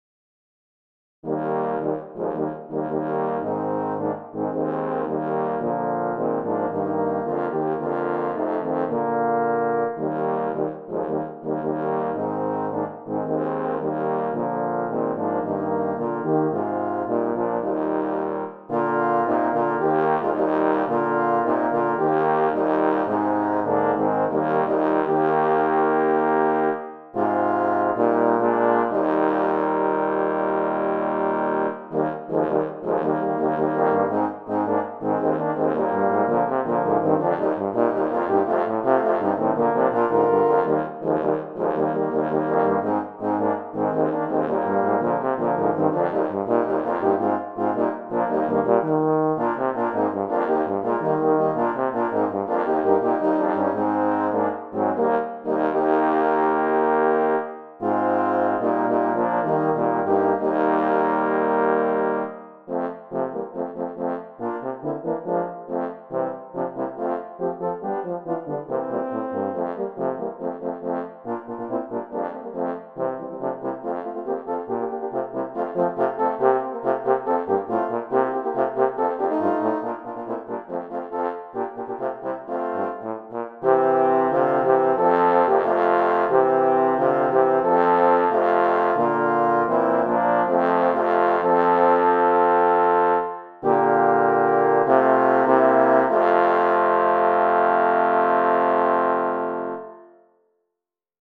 I have arranged it for horn quartet with variations in rhythm and style.